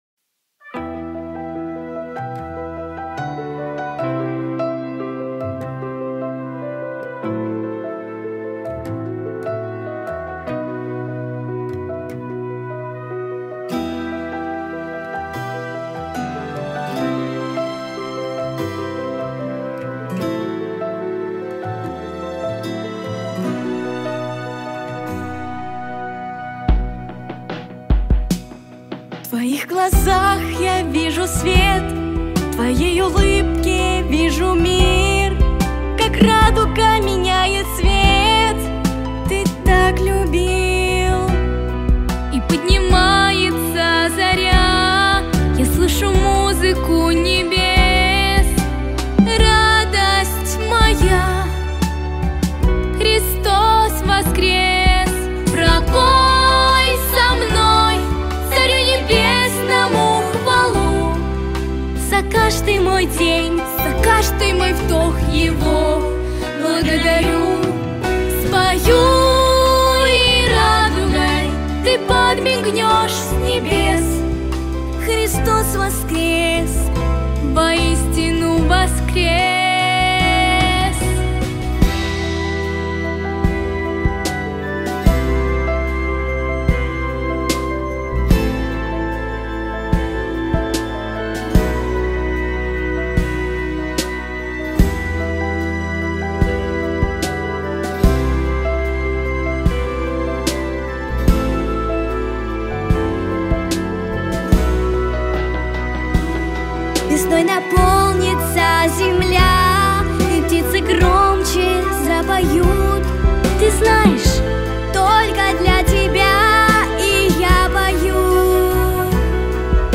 • Жанр: Детские песни
христианские песни